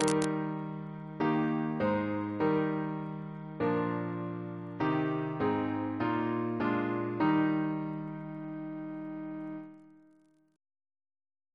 CCP: Chant sampler
Single chant in E♭ Composer: Edward F. Rimbault (1816-1876) Reference psalters: ACB: 118; ACP: 286; H1940: 610 654; PP/SNCB: 219